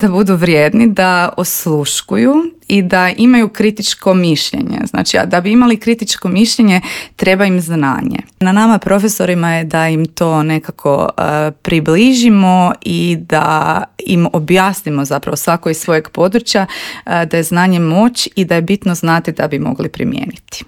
Za kraj je profesorica poslala poruku svim mladima koji žele stvarati promjene, ne samo u svojim karijerama, već i u društvu.